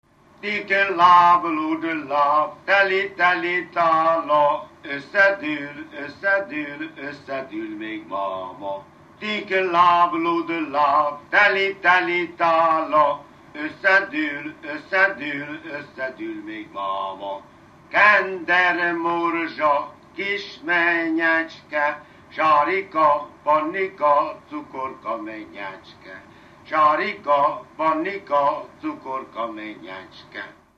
Dunántúl - Tolna vm. - Ozora
ének
Stílus: 6. Duda-kanász mulattató stílus
Kadencia: 5 (5) X X 1